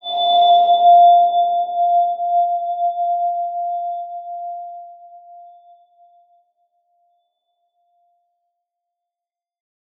X_BasicBells-F3-ff.wav